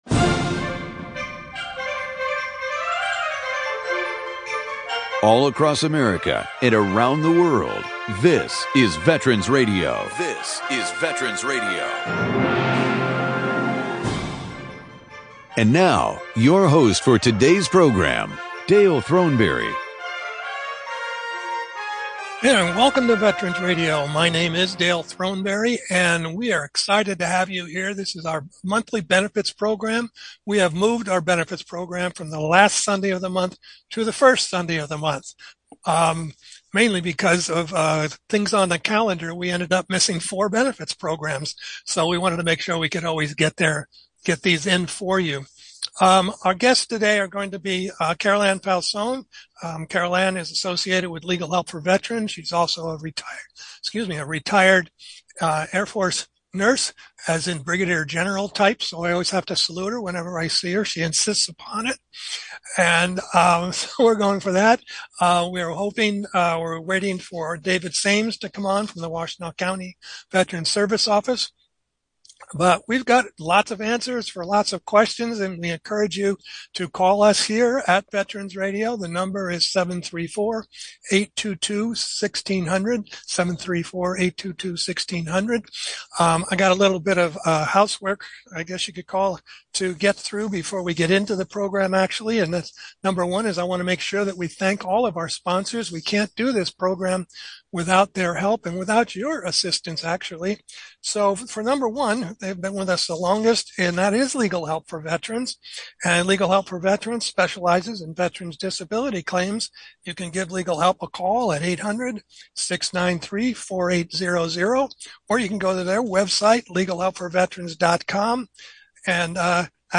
April 7, 2024: Monthly Benefits Call-in Hour
Questions about your veteran benefits? Call in to our monthly veteran benefits hour and talk with our panel of benefits experts!